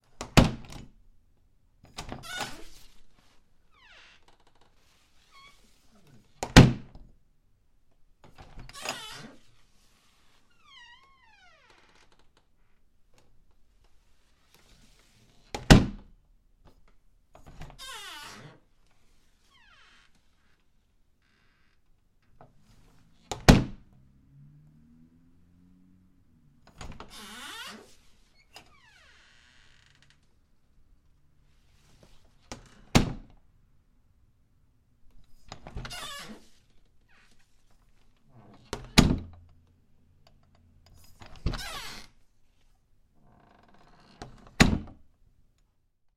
随机的 " 木门公寓浴室打开关闭时发出吱吱嘎嘎的响声关闭
描述：门木公寓浴室开放关闭与吱吱吱吱声close.flac
Tag: 打开 关闭 吱吱声 吱吱 木材 公寓 浴室